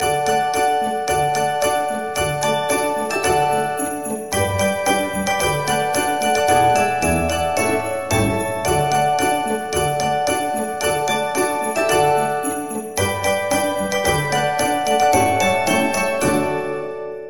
Un choix de 6 musiques d’ambiance vous est aussi offert.